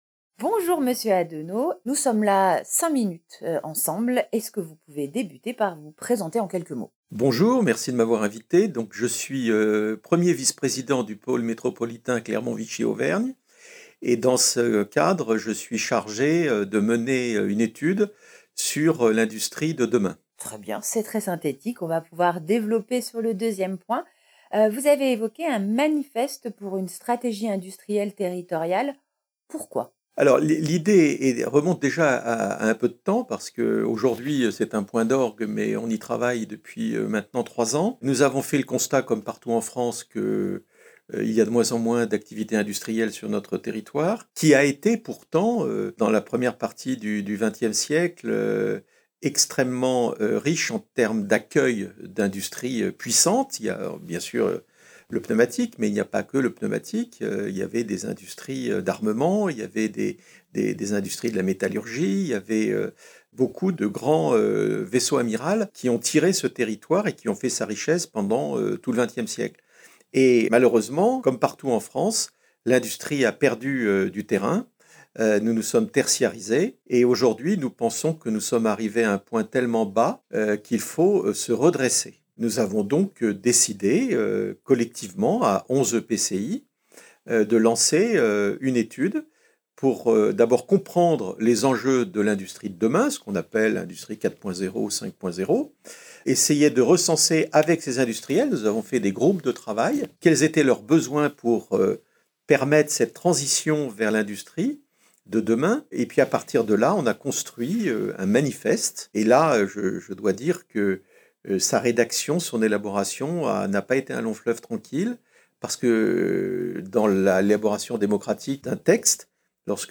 Écouter l’interview de Dominique ADENOT, 1er Vice-Président du Pôle métropolitain Clermont Vichy Auvergne, référnet sur l’économie :
Interview-DOMINIQUE-ADENOT-MIX-V1-.mp3